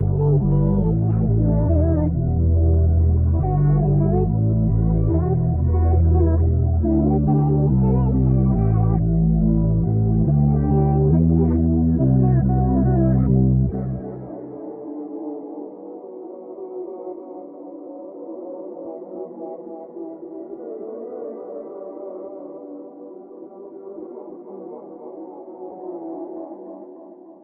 YOUR SOUL 140 BPM - FUSION.wav